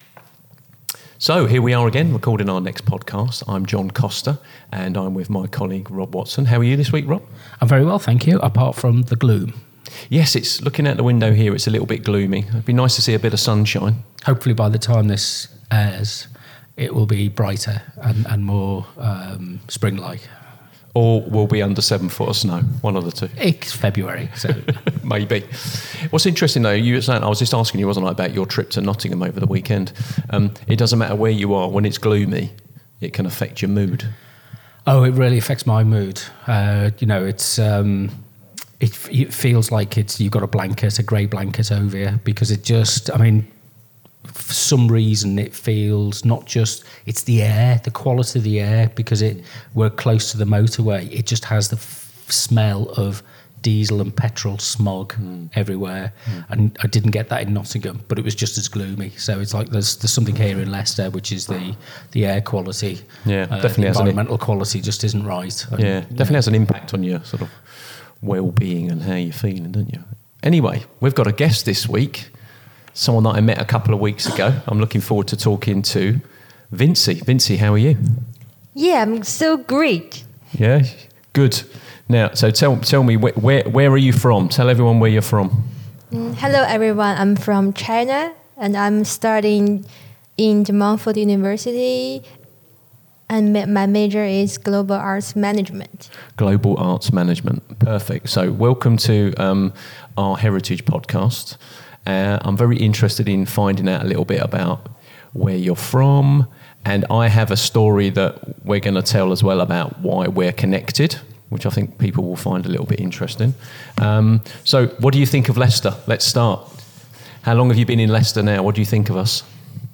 The conversation became a fascinating exchange of perspectives on the ways in which heritage is preserved, experienced, and understood in different cultural contexts.